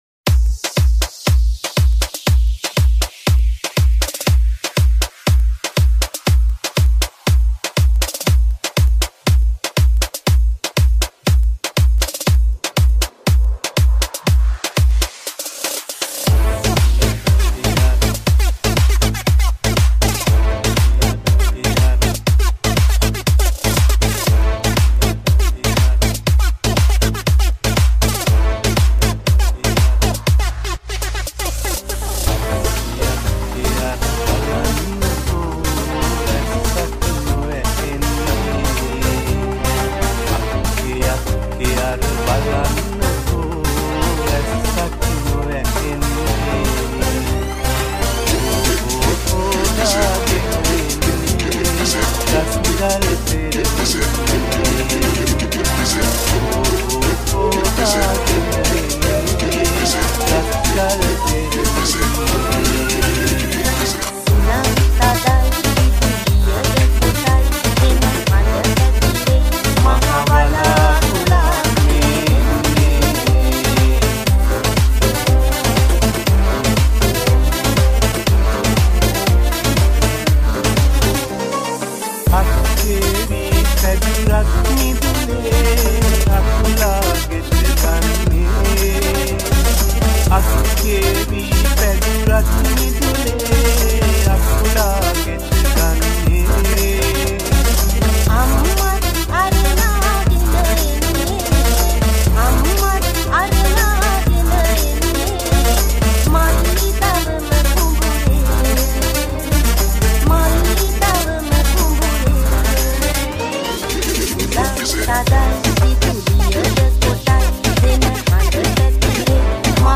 Sinhala Remix